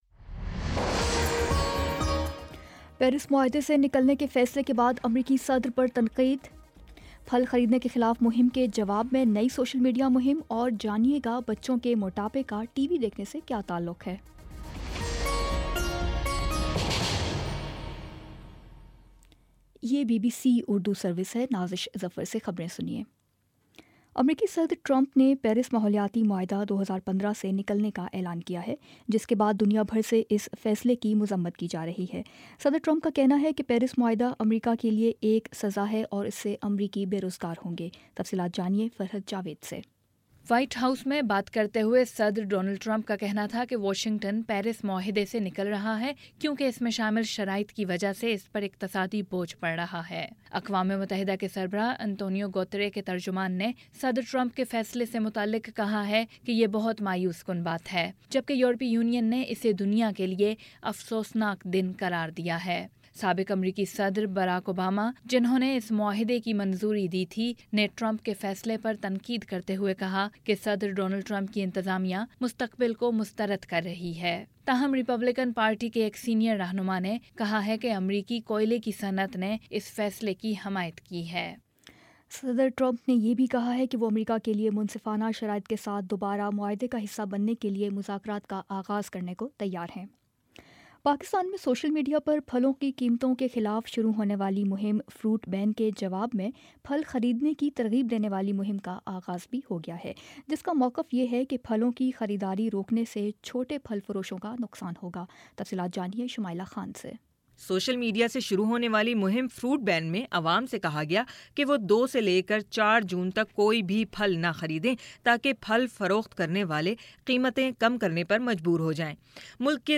جون 02 : شام پانچ بجے کا نیوز بُلیٹن